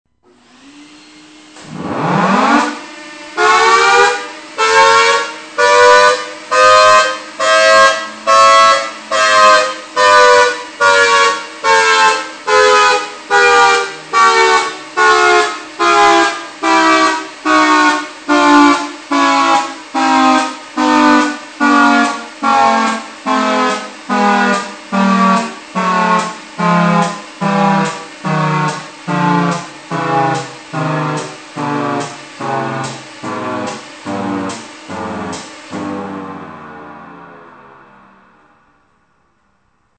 These files are a collection of siren sounds that I have downloaded and made available for all to listen to.
1003 pulsed signal
1003- Pulsed2.mp3